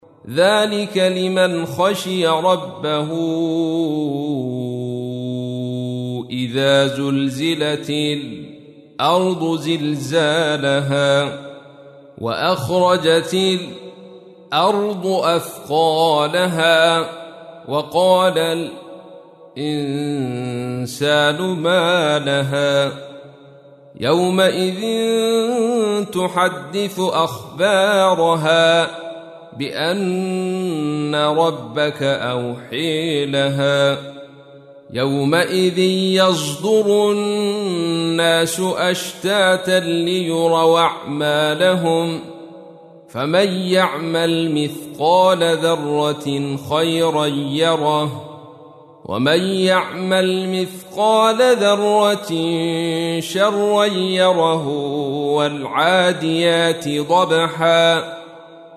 تحميل : 99. سورة الزلزلة / القارئ عبد الرشيد صوفي / القرآن الكريم / موقع يا حسين